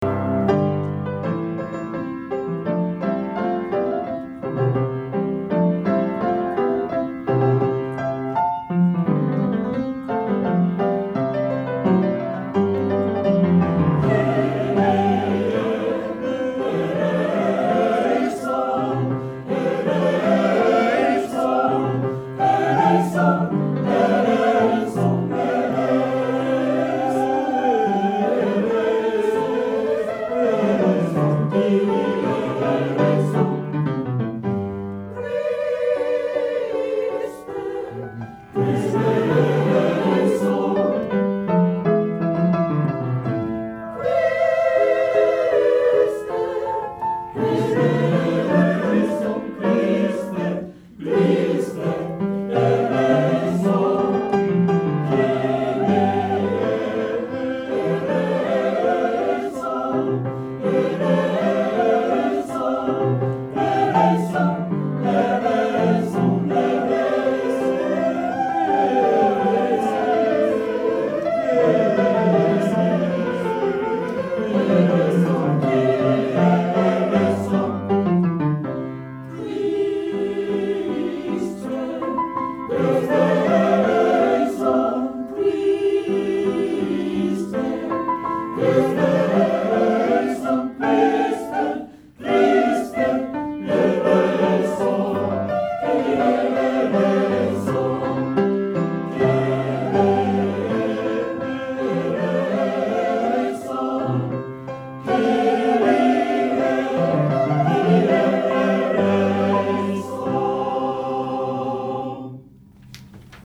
練習場所：アスピア明石北館　8階学習室801A・B（明石市）
出席者：31名（sop13、alt8、ten5、bass5）
♪=88くらいの予定